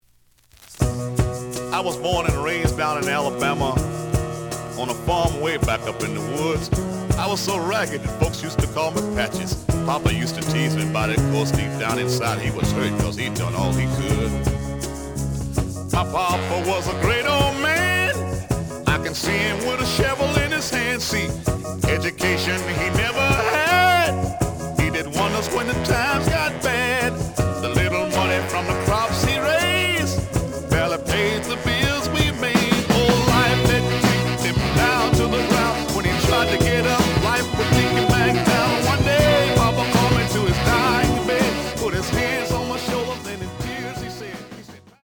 The audio sample is recorded from the actual item.
●Genre: Soul, 70's Soul
Slight noise on start of B side, but almost good.)